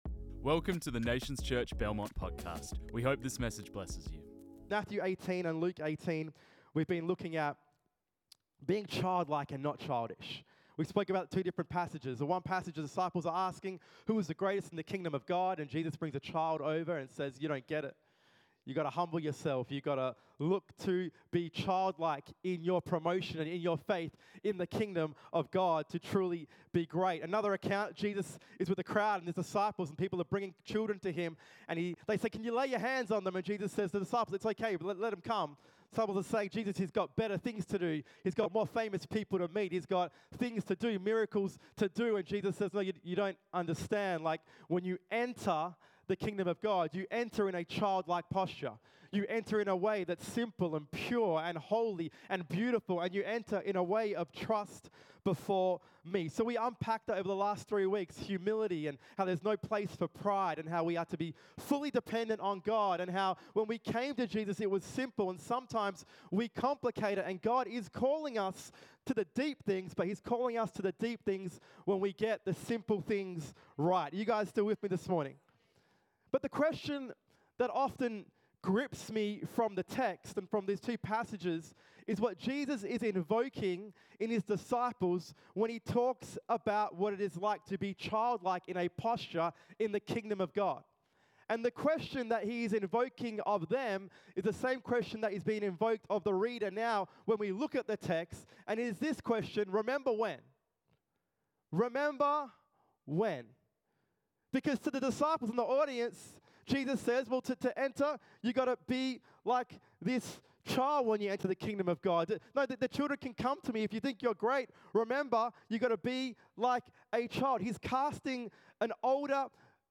This message was preached on 27 October 2024.